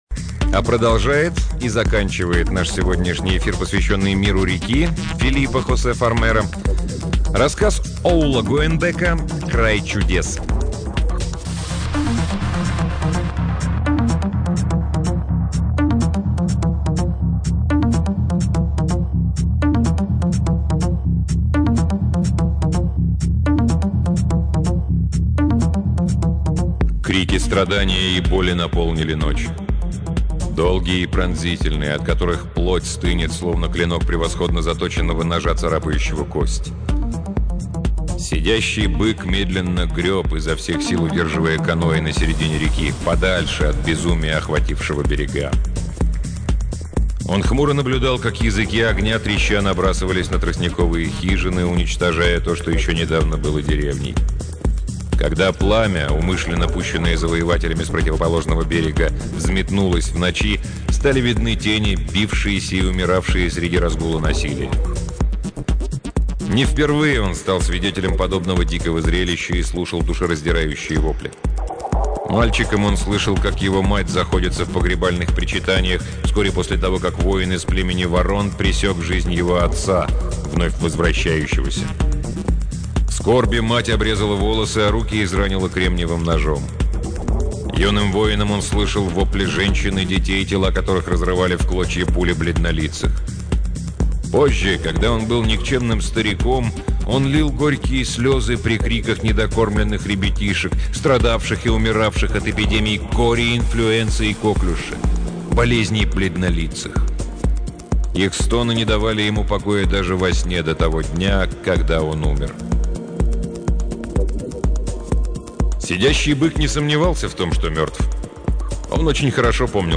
Аудиокниги передачи «Модель для сборки» онлайн